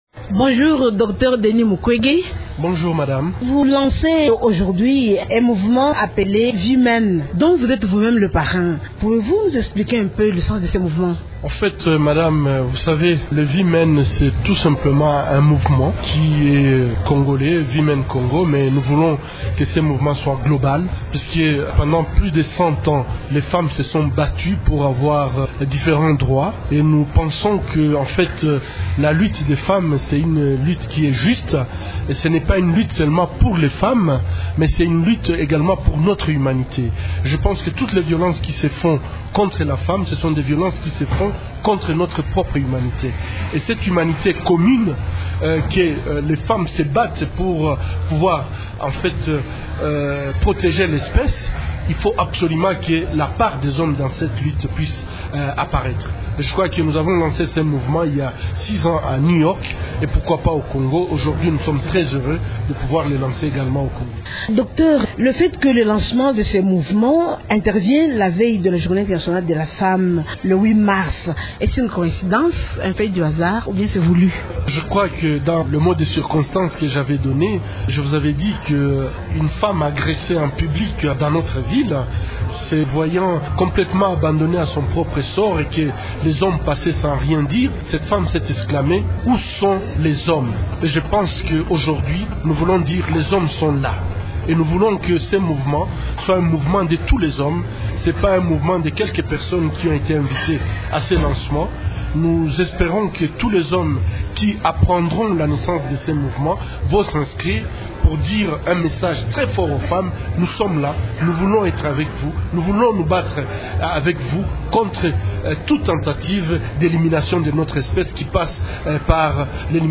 iNTERVIEUW-Dr-Deni-Mukwege.mp3